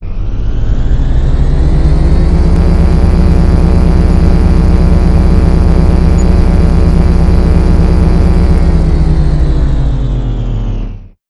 beam_activate.wav